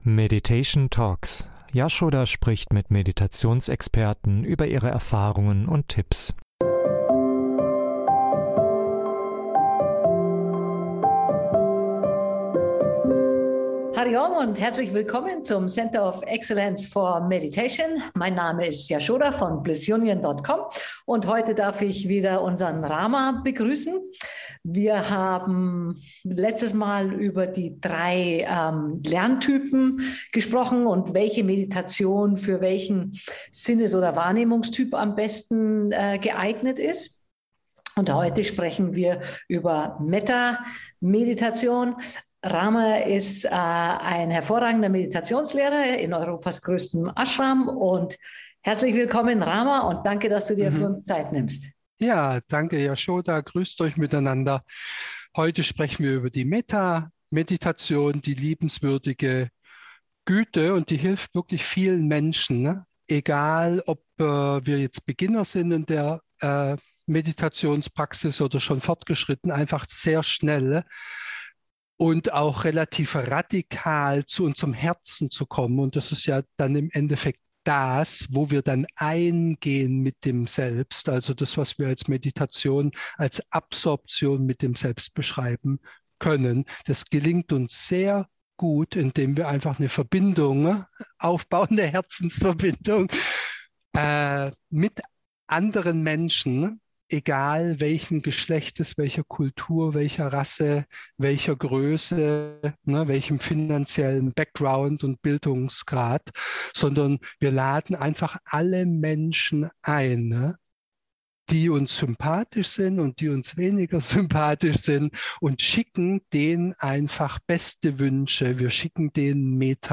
So unterstützen dich die Interviews bei der Vertiefung deiner eigenen Meditationspraxis.